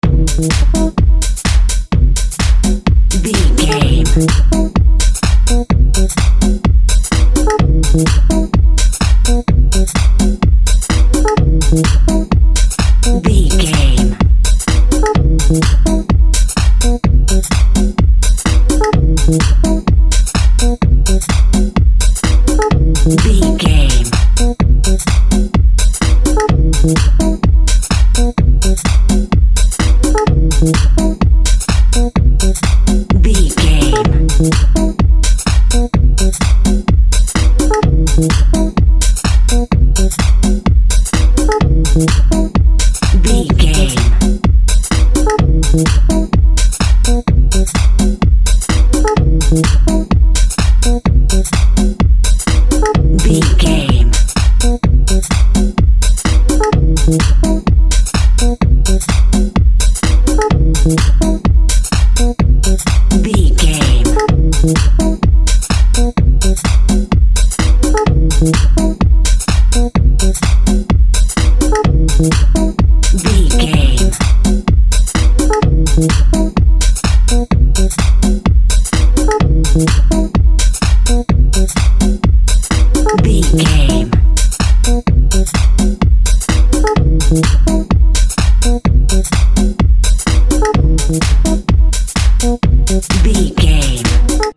Aeolian/Minor
E♭
groovy
futuristic
synthesiser
drums
drum machine
house
techno
electro
electro house
synth lead
synth bass